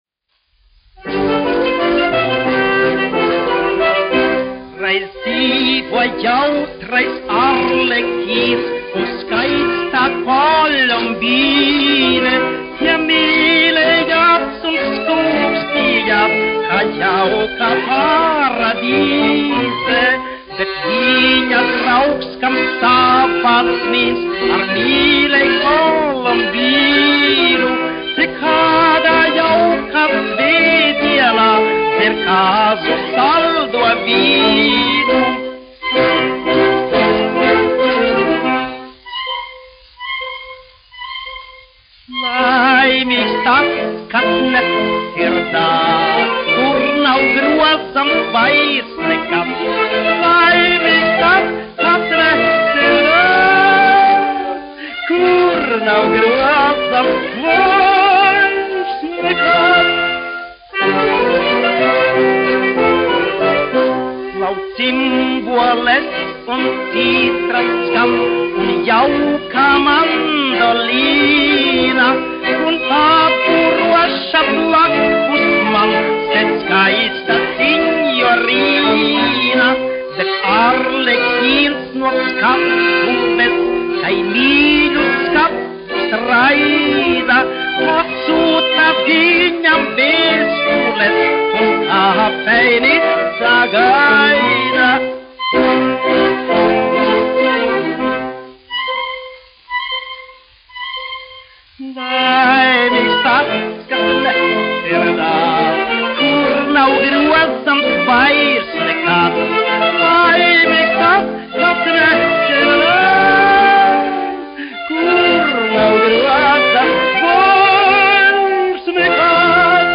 1 skpl. : analogs, 78 apgr/min, mono ; 25 cm
Populārā mūzika
Valši
Skaņuplate
Latvijas vēsturiskie šellaka skaņuplašu ieraksti (Kolekcija)